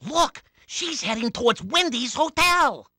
Unused voice sample of Luigi in Hotel Mario
HM_Luigi_unused_voice_sample.oga.mp3